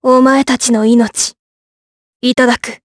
Tanya-Vox_Skill3_jp.wav